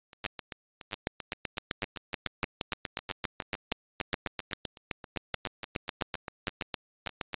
выдает по аналоговым выходам шум после включения (пример прилагается), а так же моргают индикаторы сигнала ("SIG") на передней панели.
изменение гейна не приводит к изменению уровня шума.